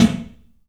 PABSNARE100R.wav